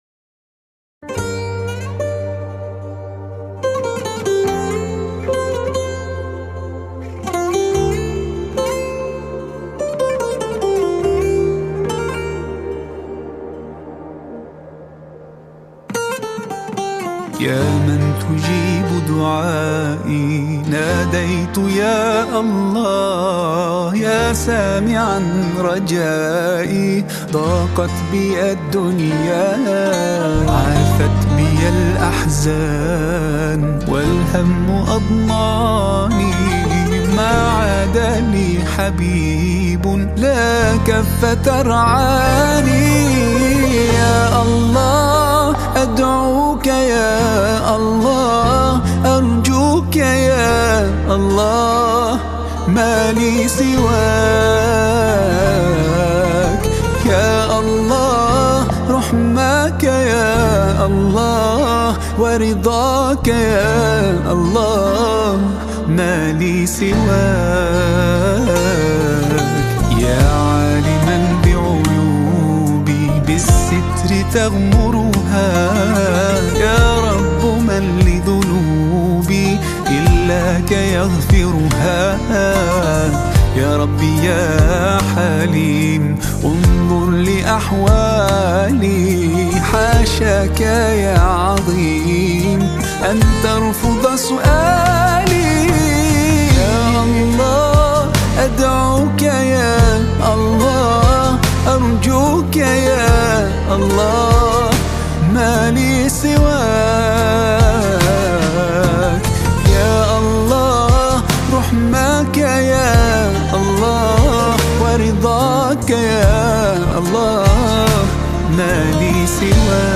Qaswida music track
Qaswida song